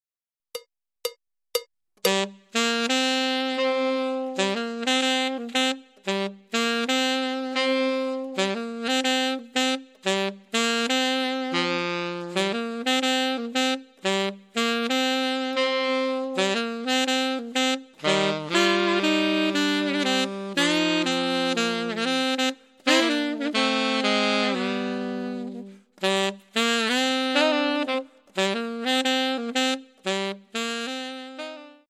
2 Saxophones